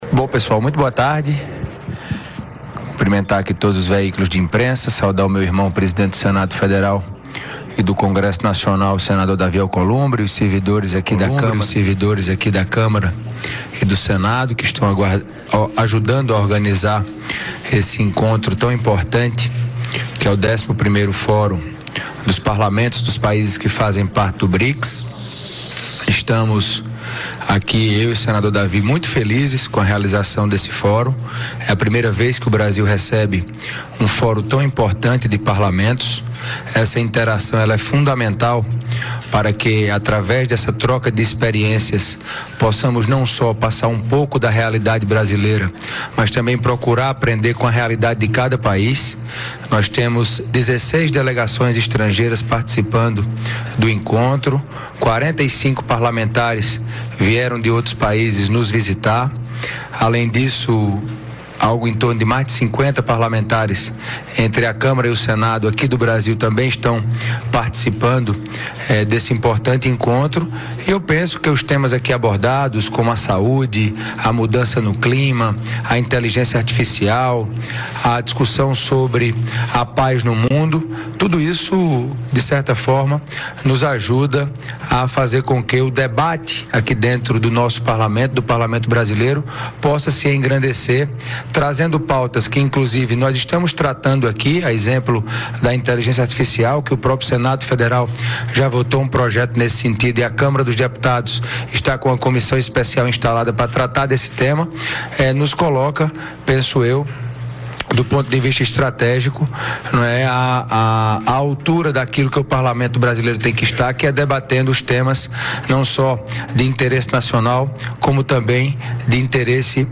Davi Alcolumbre e Hugo Motta fazem balanço do Brics em entrevista coletiva. Ouça a íntegra
Os presidentes do Senado, Davi Alcolumbre, e da Câmara dos Deputados, Hugo Motta, concederam uma entrevista coletiva, no início da tarde desta quinta-feira (5), para avaliar os três dias de debates do 11º Fórum Parlamentar do Brics. Entre os assuntos tratados pelos presidentes do Senado e da Câmara, o fortalecimento do Brics no cenário internacional.